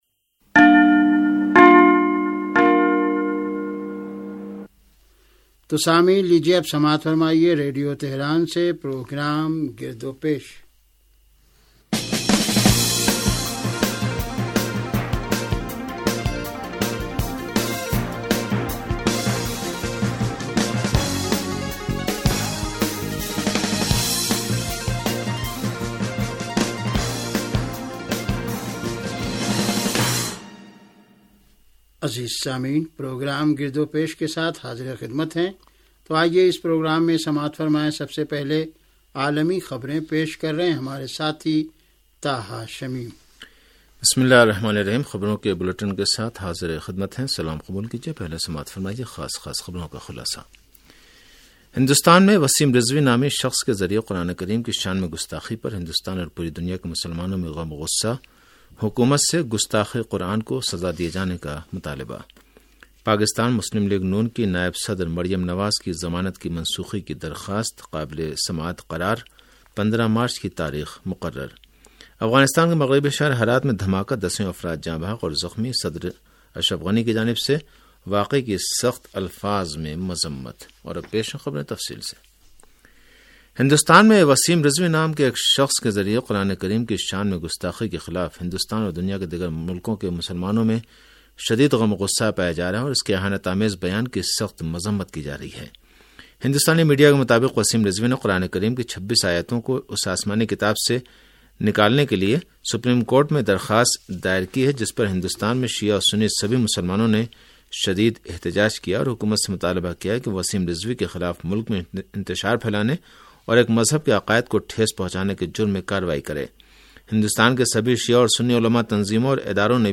ریڈیو تہران کا سیاسی پروگرام گرد و پیش